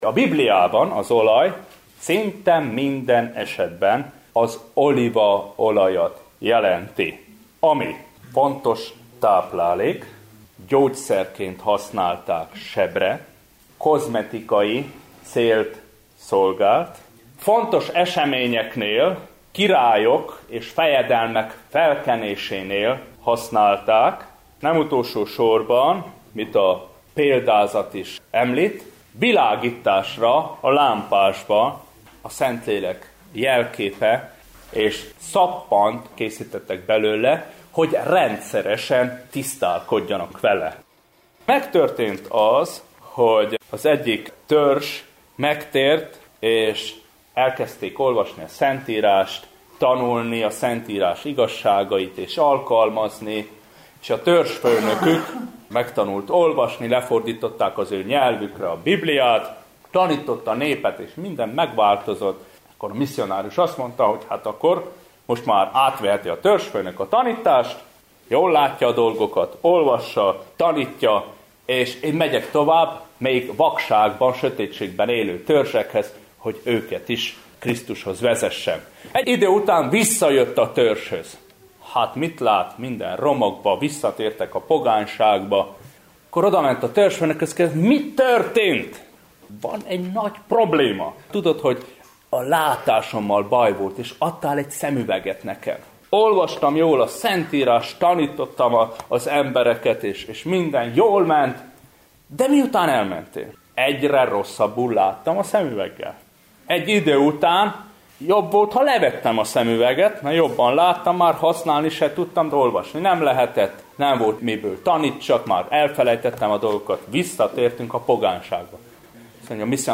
A nyári missziói konferencián